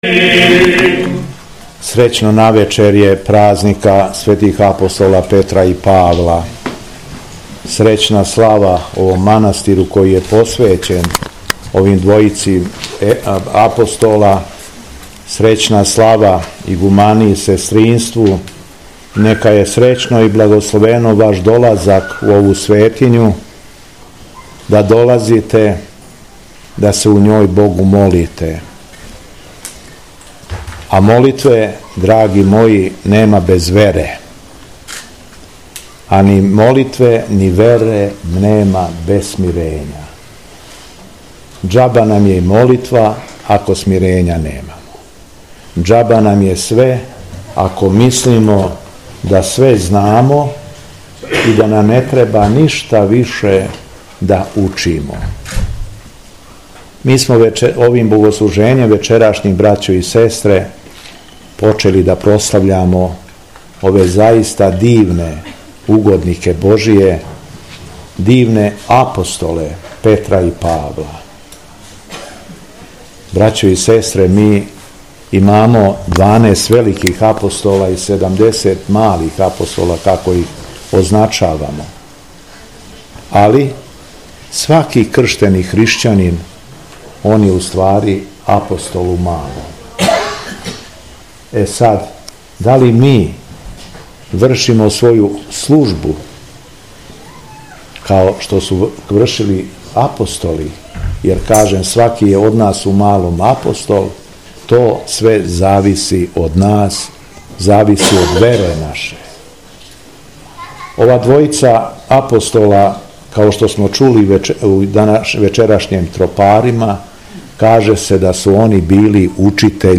Беседа Његовог Високопреосвештенства Митрополита шумадијског г. Јована
Након молитве митрополит Јован обратио се верном народу следећим речима поуке: